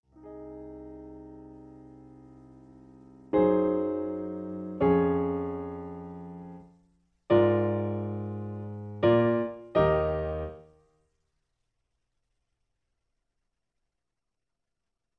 In G sharp. Piano Accompaniment